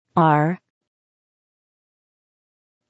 Note: il s'agit ici des prononciations anglaises.